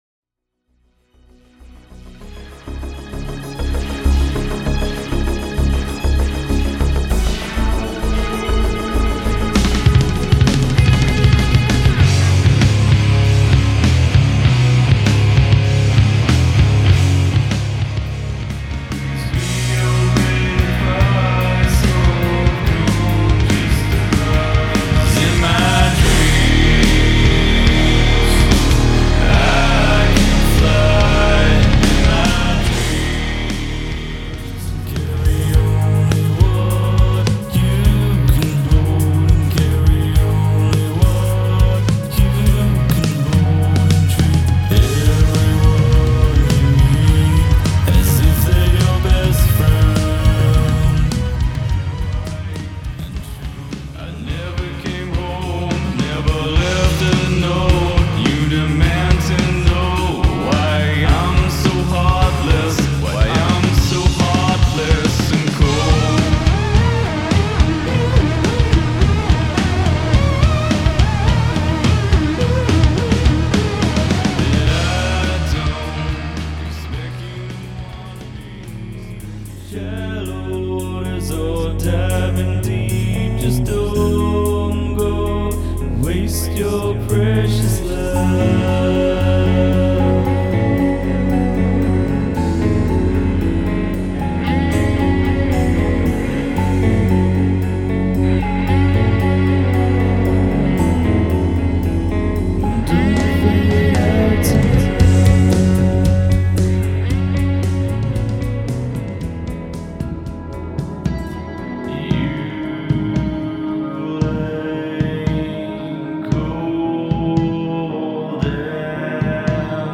Bass & Vocal
Guitar & Keys
Keyboard
Drums
original progressive rock band
with a splash of ‘80s-inspired synth